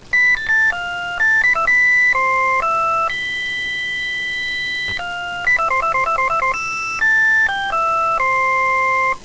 atf-ring1.wav